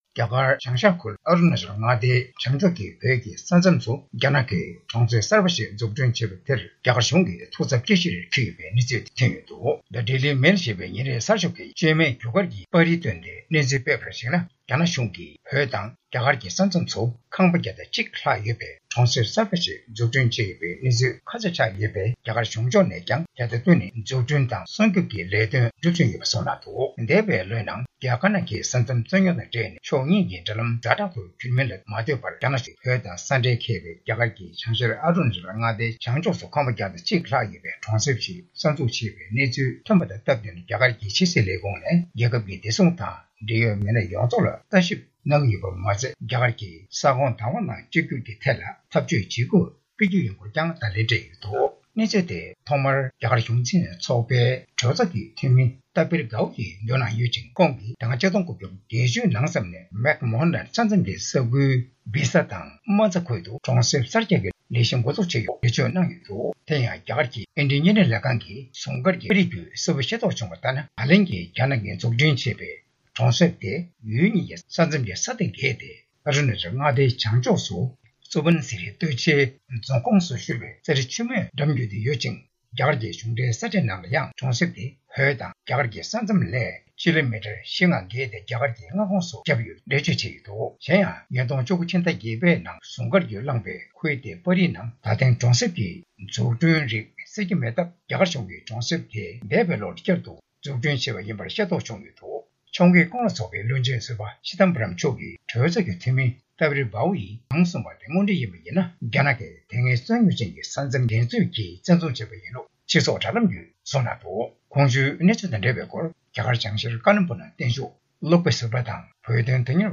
༄༅།།གསར་འགྱུར་དཔྱད་གཏམ་གྱི་ལེ་ཚན་ནང་།རྒྱ་གར་བྱང་ཤར་ཨ་རུ་ན་ཅལ་མངའ་སྡེའི་བྱང་ཕྱོགས་བོད་ཀྱི་ས་མཚམས་སུ་རྒྱ་ནག་གིས་གྲོང་གསེབ་གསར་པ་ཞིག་འཛུགས་སྐྲུན་བྱས་པ་དེར་རྒྱ་གར་གཞུང་ཐུགས་འཚབ་སྐྱེ་གཞིར་གྱུར་ཡོད་པའི་གནས་ཚུལ་དང་འབྲེལ་བའི་སྐོར་རྒྱ་གར་བྱང་ཤར་ཀ་ལིམ་པོངྒ་